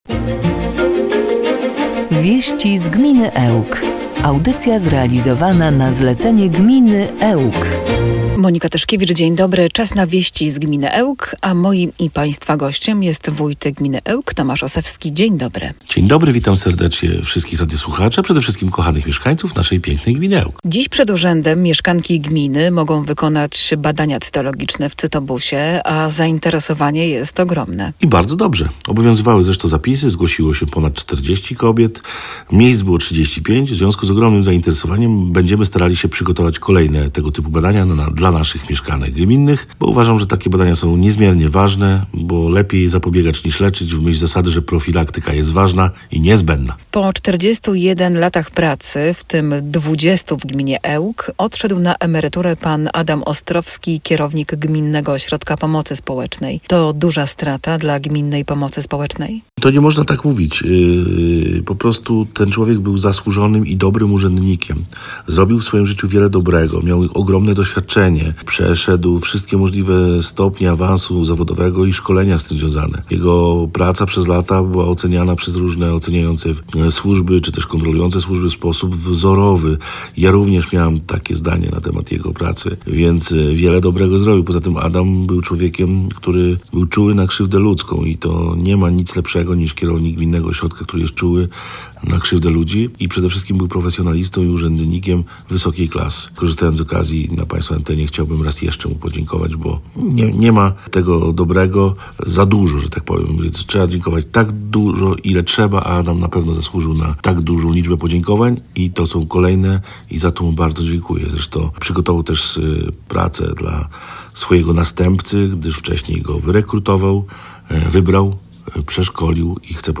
Utworzenie ełckiej filii Wojewódzkiego Ośrodka Ruchu Drogowego w Olsztynie, bezpłatne badania cytologiczne i zaproszenie na Warsztaty Wielkanocne – między innymi te zagadnienia poruszył w audycji „Wieści z gminy Ełk” na antenie Radia 5 Tomasz Osewski, wójt gminy Ełk. Zachęcamy do wysłuchania rozmowy.
4.04-WIESCI-Z-GMINY-ELK-z-jinglami.mp3